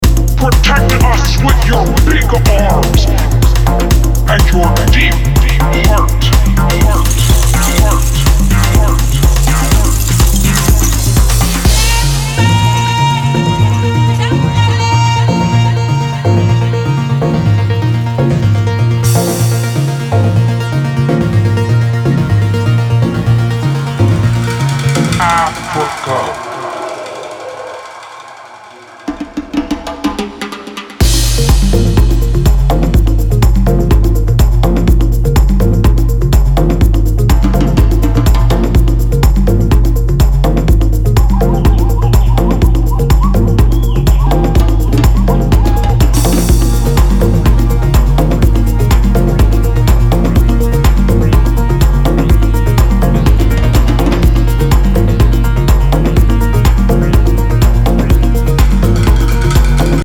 his contemplative voices
• Afro House